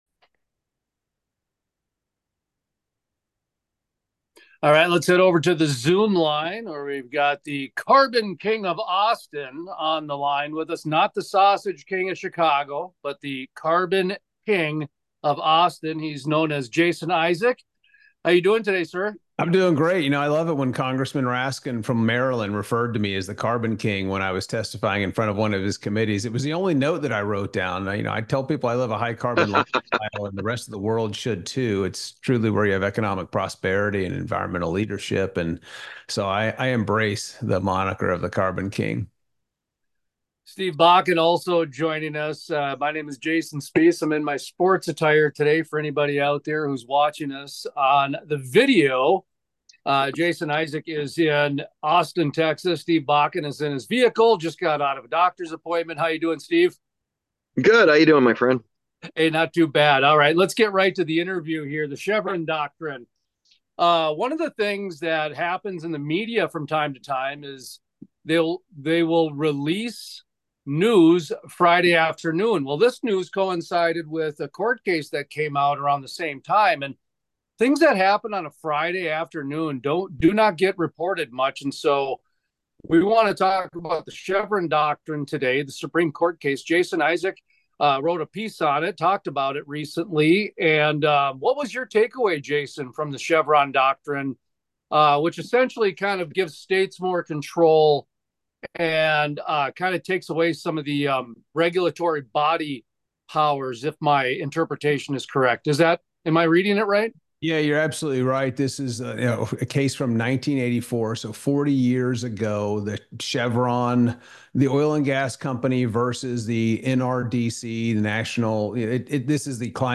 Full Length Interviews How the Chevron Deference Impacts Energy Play Episode Pause Episode Mute/Unmute Episode Rewind 10 Seconds 1x Fast Forward 10 seconds 00:00 / 55:40 Subscribe Share RSS Feed Share Link Embed